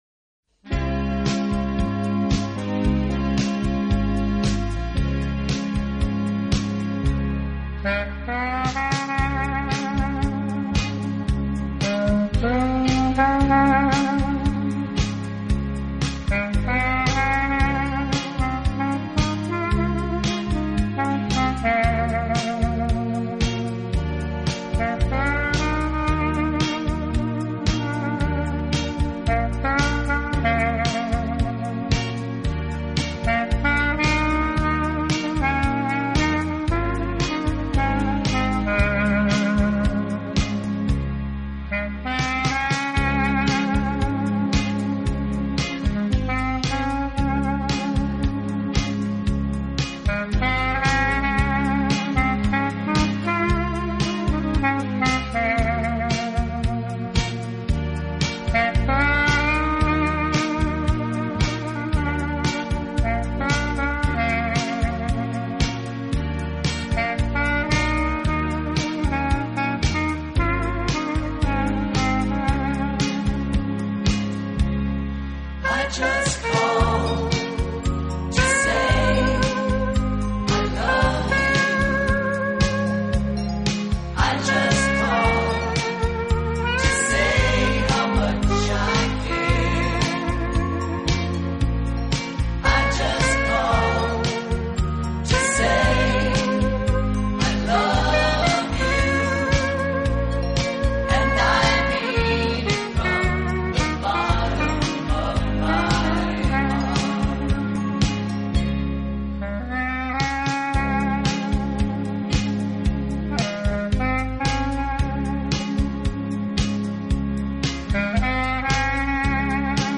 Album Type(s): Greatest Hits, Instrumental
Genre: Jazz
Styles: Early Pop/Rock, Trad Jazz